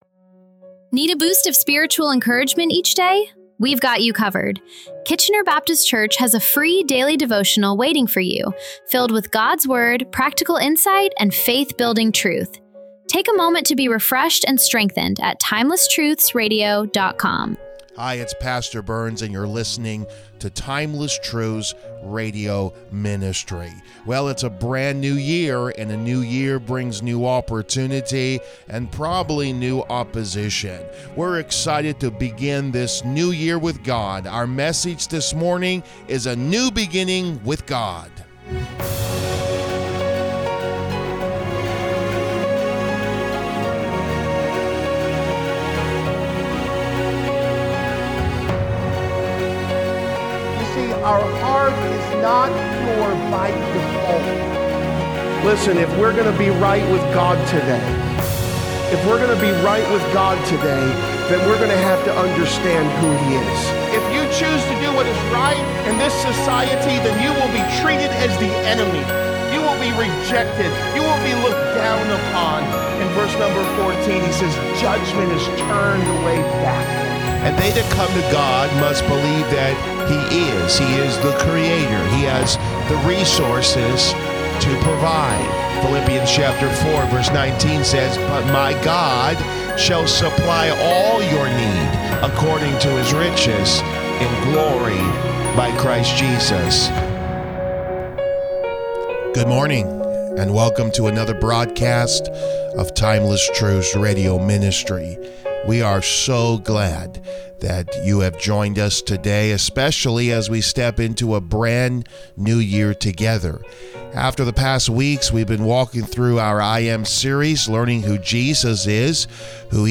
This sermon also challenges us to recognize that real change begins on the inside.